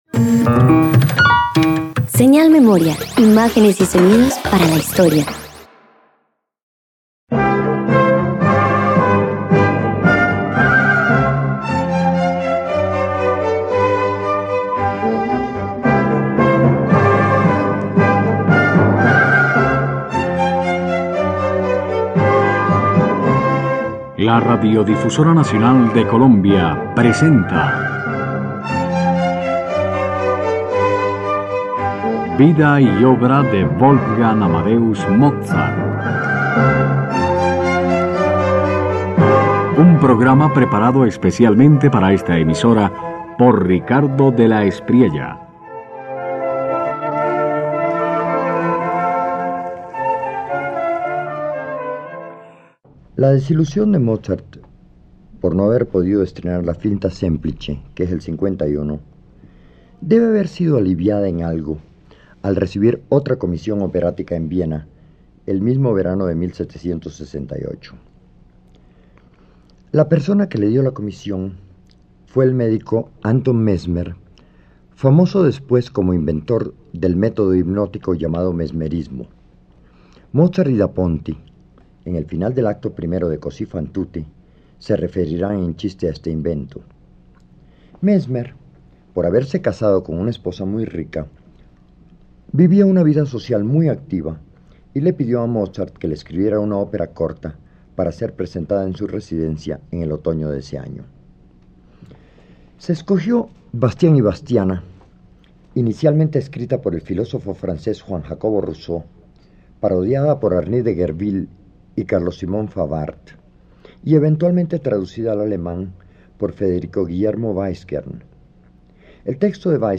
En esta obra pastoril, Mozart eleva lo ingenuo a categoría artística: melodías sencillas que suenan a canto popular, pero que respiran con orden y gracia. No ridiculiza lo rústico, sino que lo ennoblece con ternura delicada y afecto.